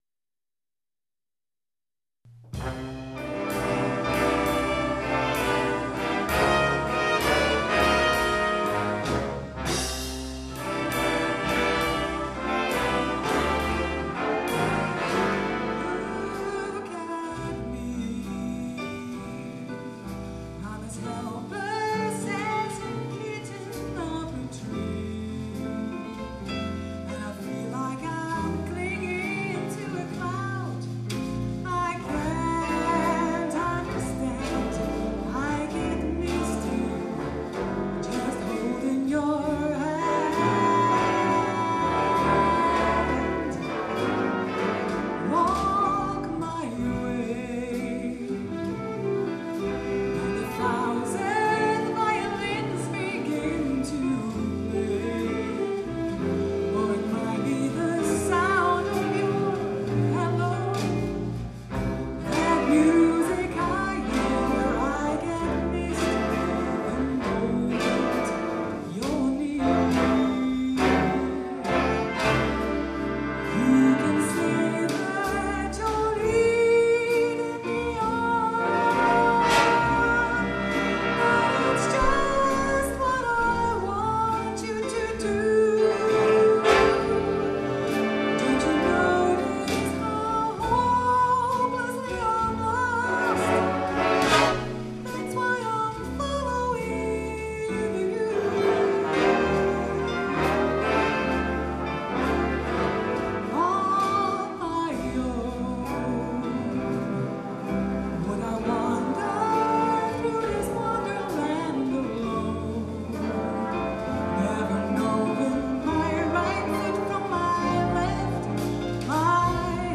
· Genre (Stil): Big Band